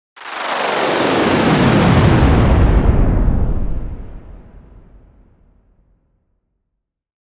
Powerful Slowing Down Sound Effect
Description: Powerful slowing down sound effect. Strong, intense, and dramatic sound of deceleration, landing, or energy disruption.
Powerful-slowing-down-sound-effect.mp3